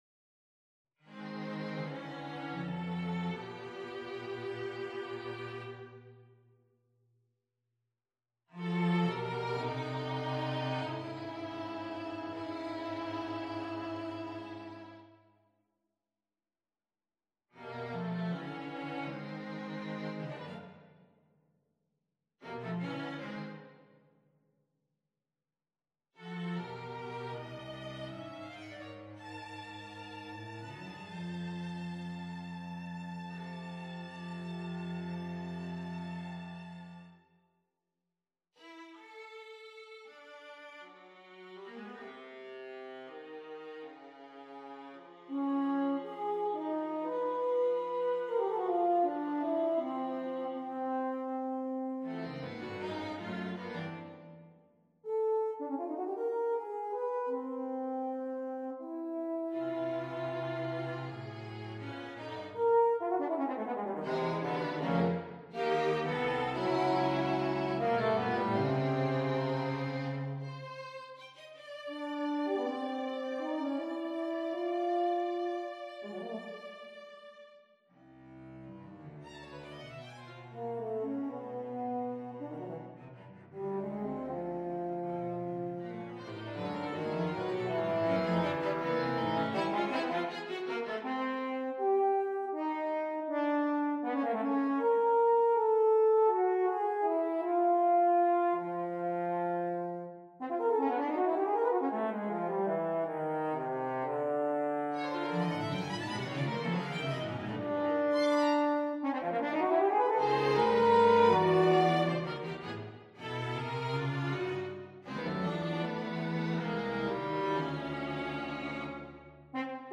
Quartet for Horn and Strings
on a purpose-selected tone row
(1) Allegro molto
(2) Scherzo. Prestissimo - Prestississimo - Tempo Primo - Trio abbreviato. Presto ma non troppo - Prestissimo - Prestississimo - Tempo Secondo
(3) Grave con moto - Lento assai - Adagietto
(4) Finale. Allegro energico - Poco piu mosso
A slender, but earnest interlude.